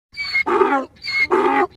donkey-neigh
donkey-neigh.mp3